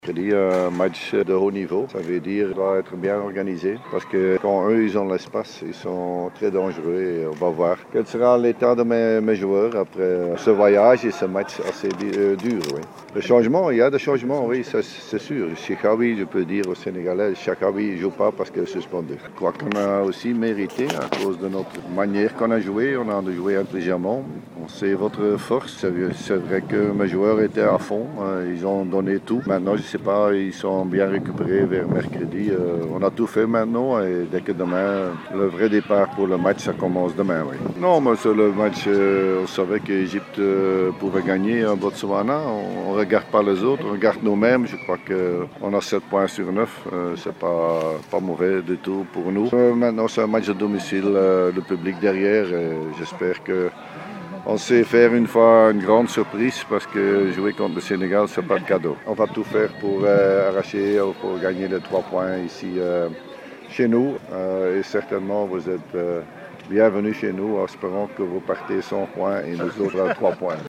و قبل انطلاق الحصة التدريبية تحدث مراسلنا مع مدرب المنتخب التونسي جورج ليكنس و بعض اللاعبين على مباراة تونس و السينغال.